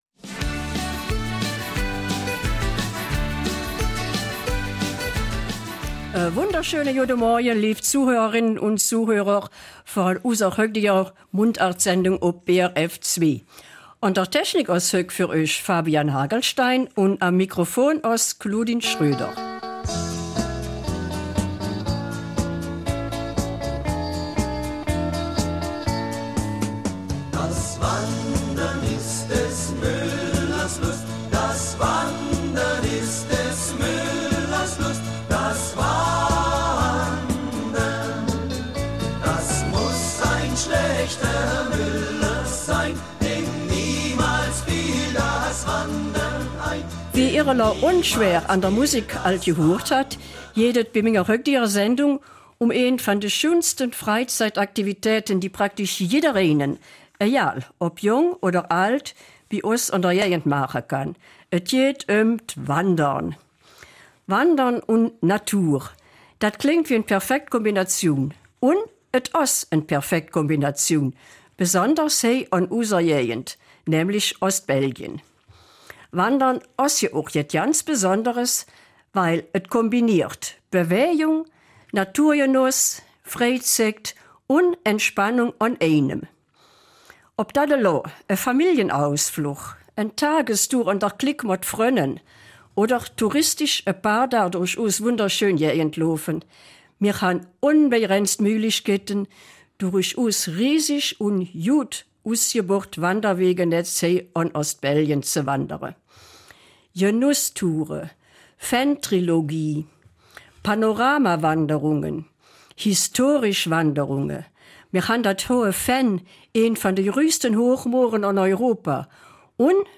Eifeler Mundart - 7.